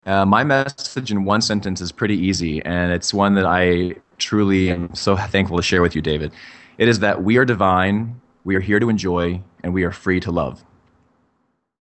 The Interview: